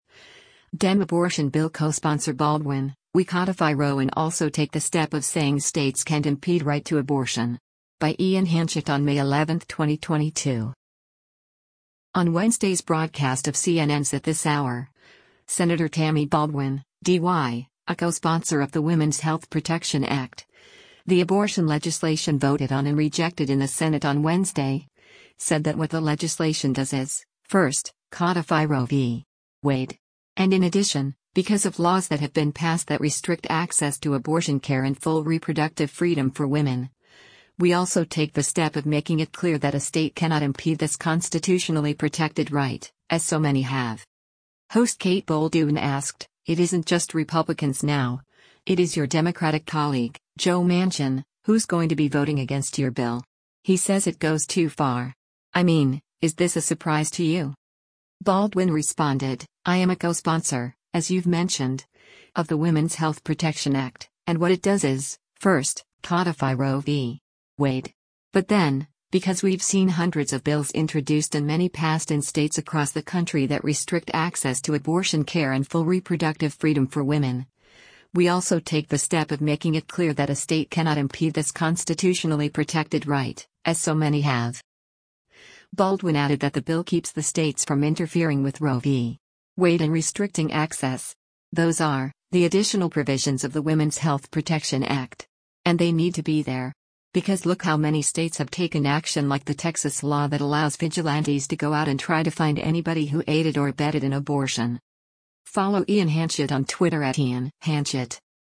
On Wednesday’s broadcast of CNN’s “At This Hour,” Sen. Tammy Baldwin (D-WI), a co-sponsor of the Women’s Health Protection Act, the abortion legislation voted on and rejected in the Senate on Wednesday, said that what the legislation “does is, first, codify Roe v. Wade.”
Host Kate Bolduan asked, “It isn’t just Republicans now, it is your Democratic colleague, Joe Manchin, who’s going to be voting against your bill.